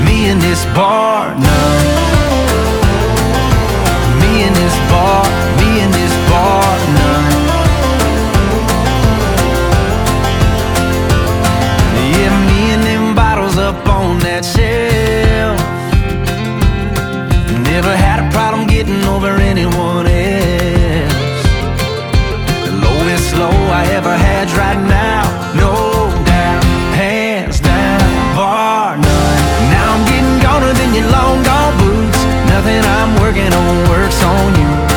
2025-03-28 Жанр: Кантри Длительность